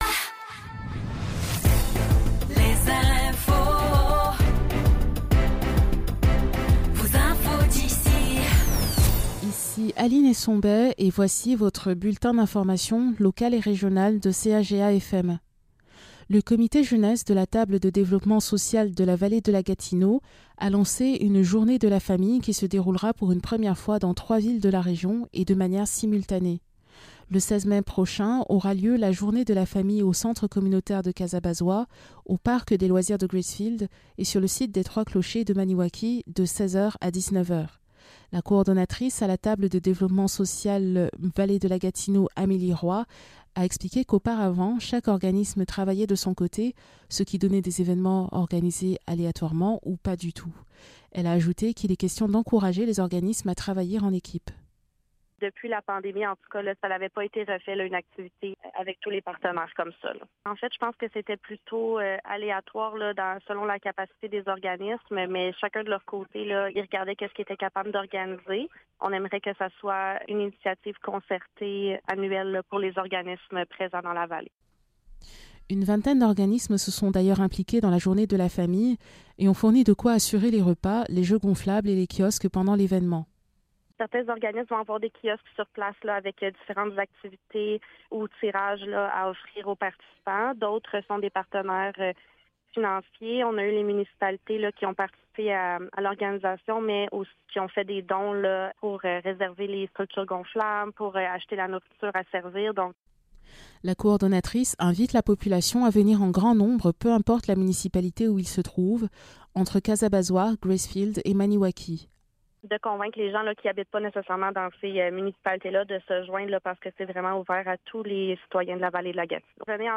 Nouvelles locales - 10 mai 2024 - 12 h